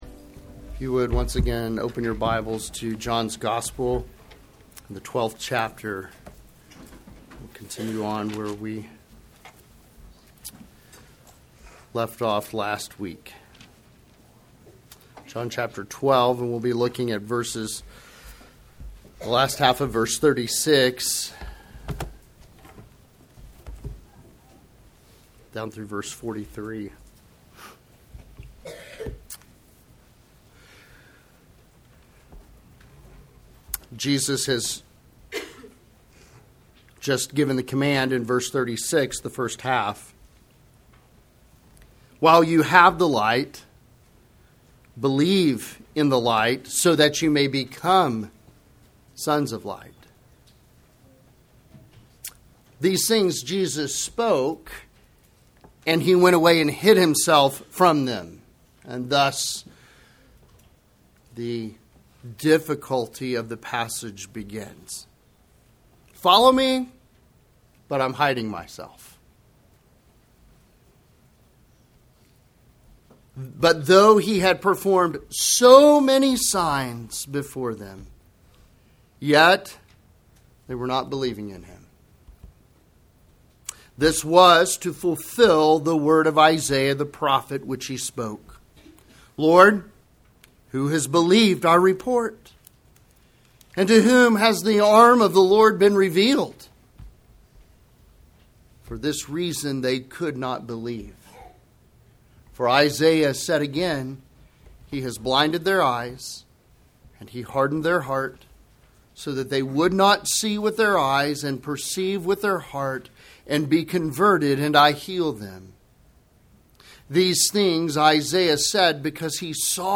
Sermons
sermon-april-6-2025.mp3